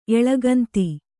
♪ eḷaganti